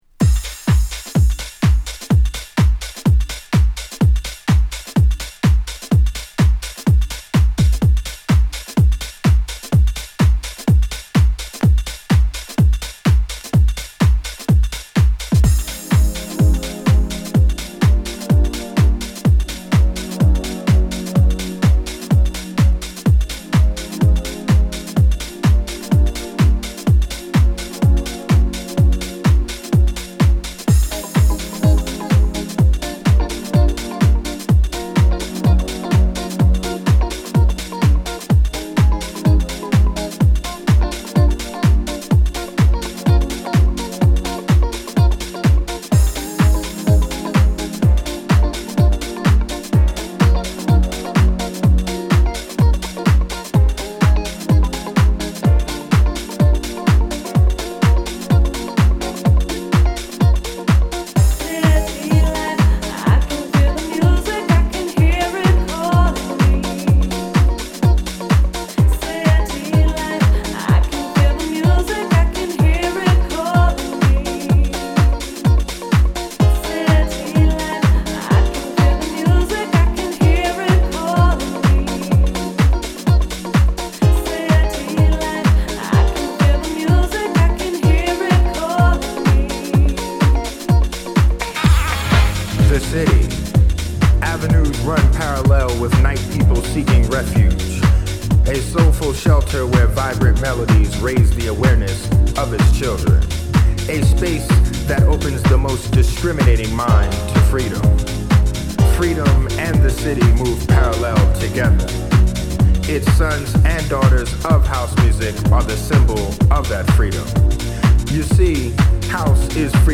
NY / NJのオールドスクーラーのヴァイブをしっかりと引き継いだ男臭いドラムに、ソウルフルなギターと女性コーラス。
中盤からのエモーショナルな展開も良いです！